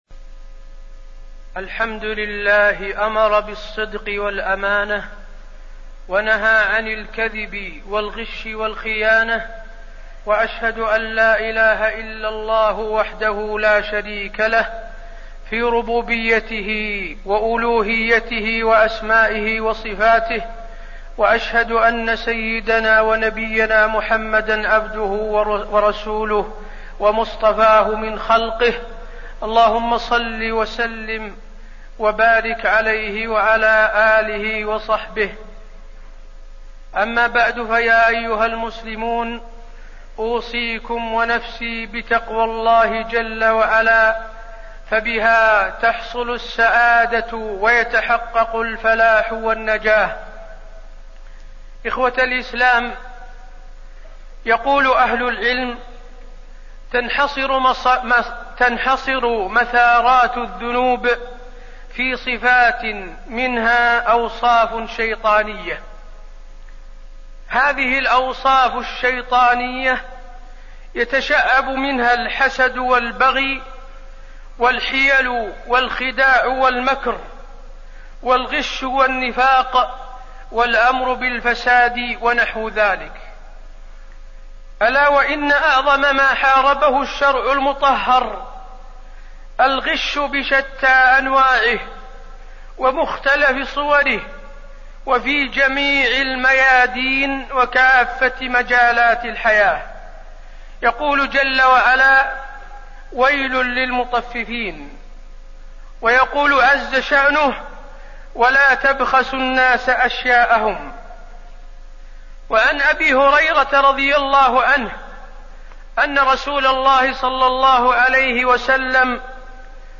تاريخ النشر ٥ ربيع الأول ١٤٣١ هـ المكان: المسجد النبوي الشيخ: فضيلة الشيخ د. حسين بن عبدالعزيز آل الشيخ فضيلة الشيخ د. حسين بن عبدالعزيز آل الشيخ الغش